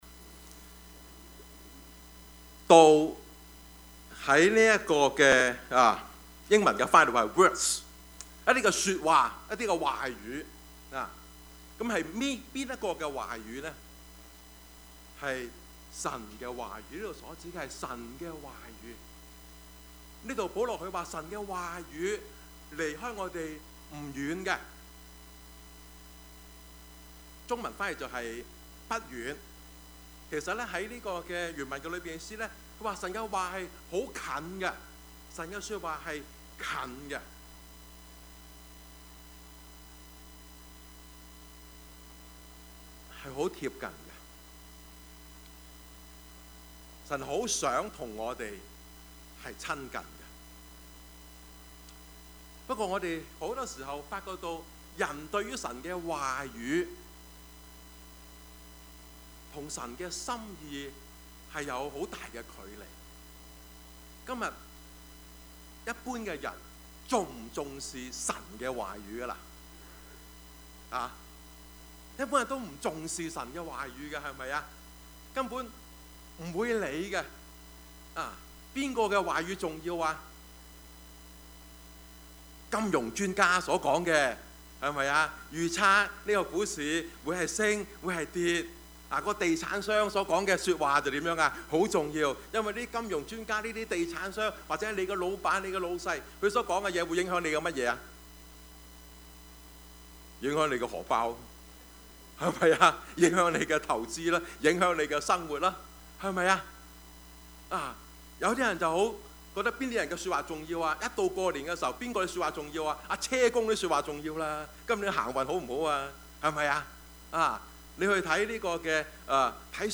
Service Type: 主日崇拜
Topics: 主日證道 « 主啊！